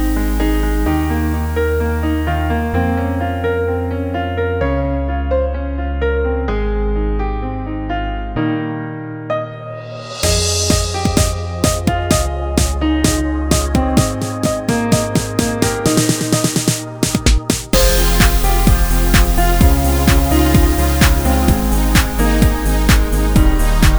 With no Backing Vocals Pop (2000s)